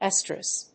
音節es・trus 発音記号・読み方
/éstrəs(米国英語), íːsrəs(英国英語)/